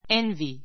envy A2 énvi エ ンヴィ 動詞 三単現 envies énviz エ ンヴィ ズ 過去形・過去分詞 envied énvid エ ンヴィ ド -ing形 envying énviiŋ エ ンヴィイン ぐ 羨 うらや む, 妬 ねた む I envy you.